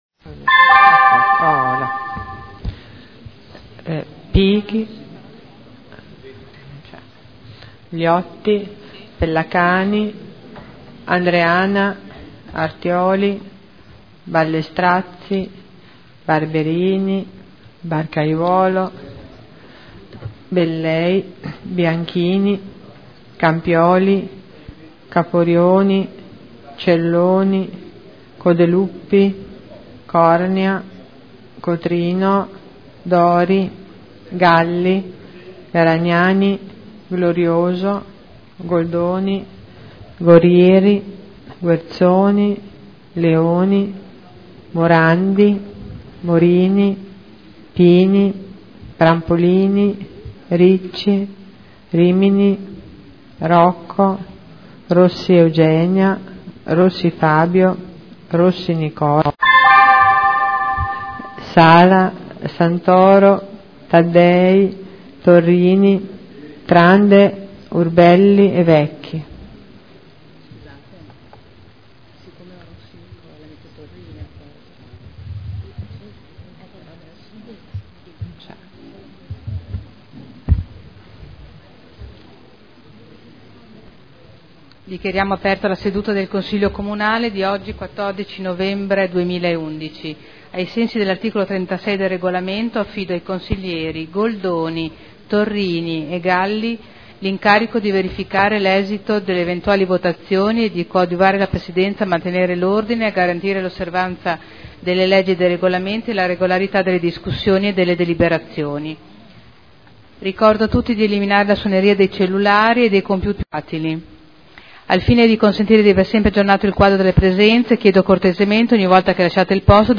Seduta del 14 novembre Apertura del Consiglio Comunale Appello